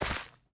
Minecraft Version Minecraft Version 21w07a Latest Release | Latest Snapshot 21w07a / assets / minecraft / sounds / block / rooted_dirt / step3.ogg Compare With Compare With Latest Release | Latest Snapshot
step3.ogg